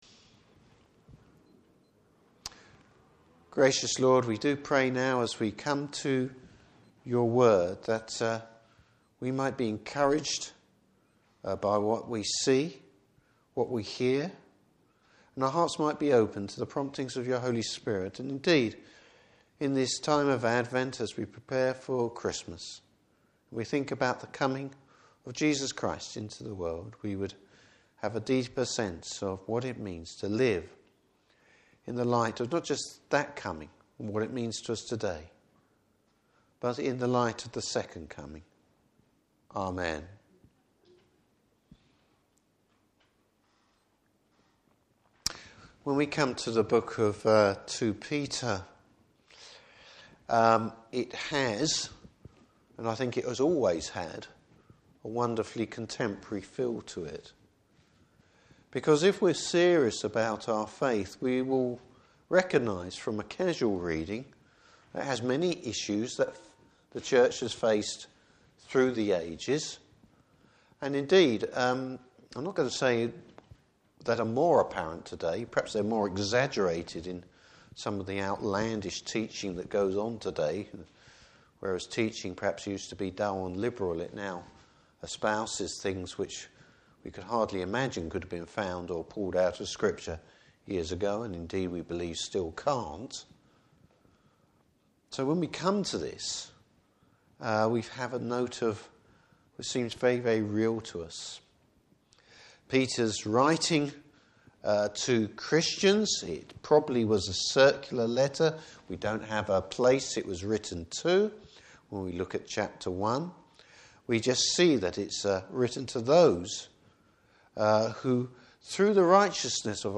Service Type: Evening Service Bible Text: 2 Peter 3:8-14.